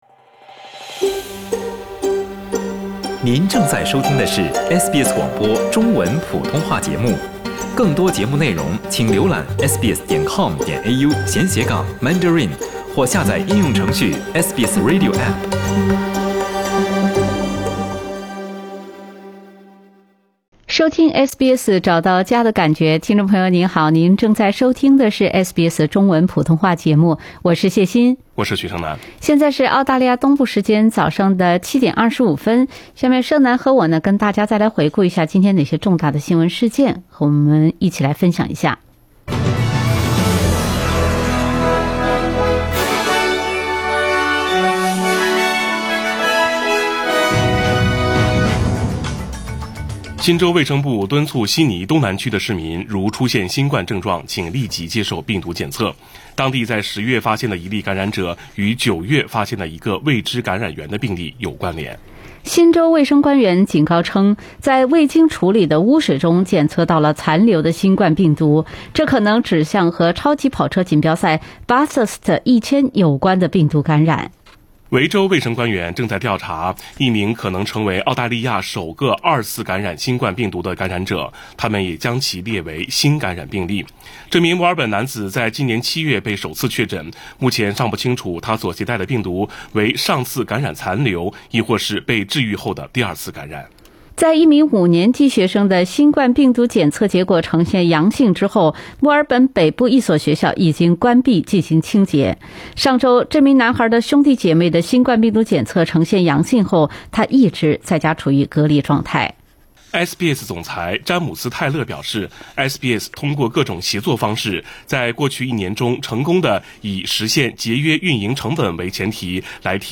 SBS早新聞（10月22日）